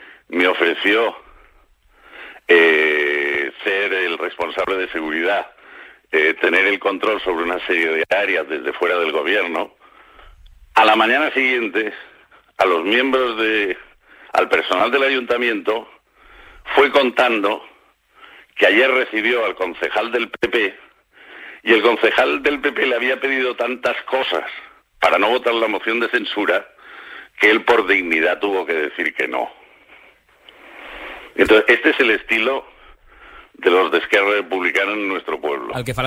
El regidor del PP a Calonge i Sant Antoni, Alberto Mas, serà tinent d’alcalde encarregat de seguretat i urbanitzacions. En una entrevista al Supermatí de Ràdio Capital ha explicat que tindrà en el punt de mira les okupacions i el tràfic de droga al municipi, i que vol incrementar la plantilla del cos, “que en necessita”.